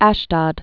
(ăshdŏd, äsh-dōd)